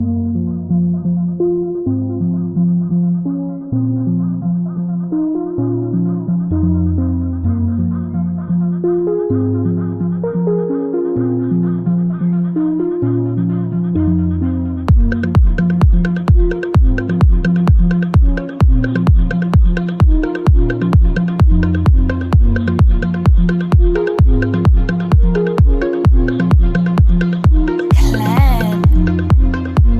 Electronic Pulse
Electronic Pulse is a free music sound effect available for download in MP3 format.
055_electronic_pulse.mp3